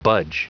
Prononciation du mot budge en anglais (fichier audio)
Prononciation du mot : budge